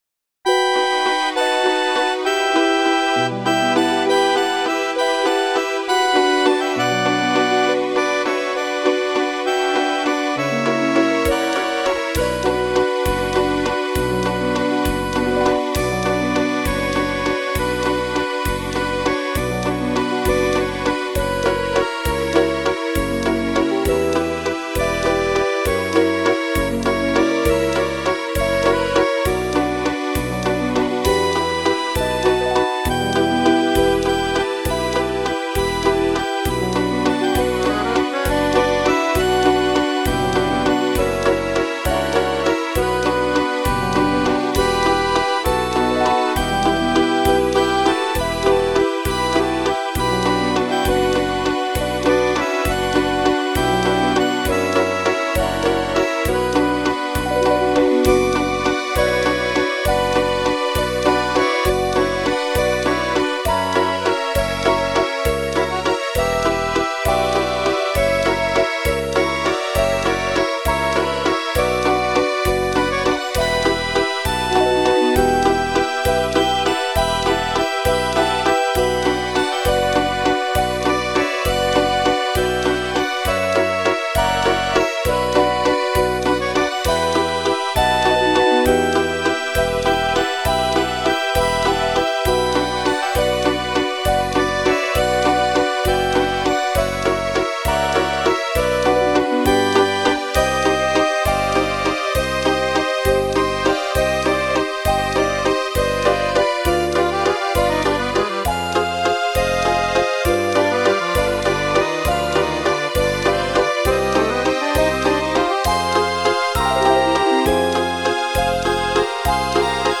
Если попроще - караочный вариант